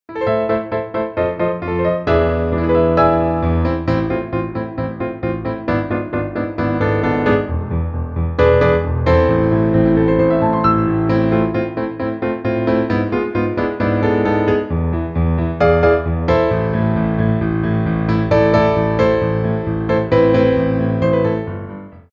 2/4 (16x8)